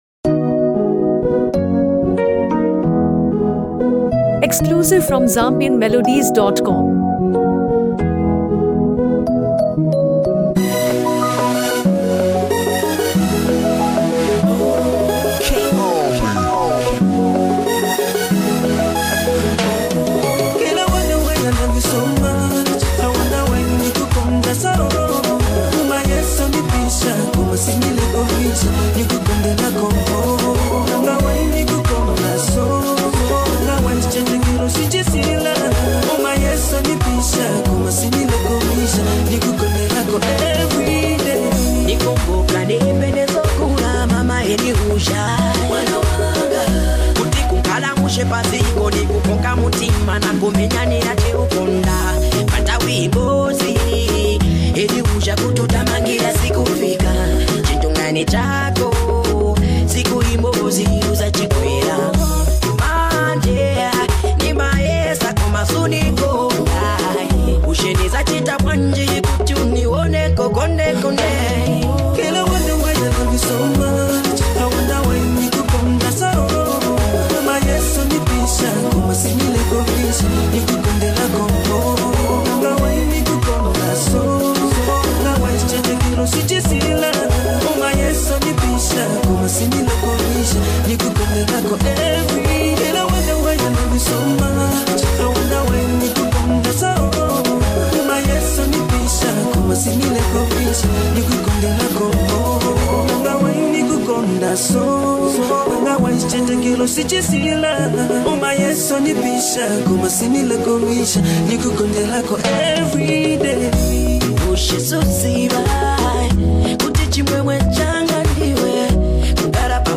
emotionally charged single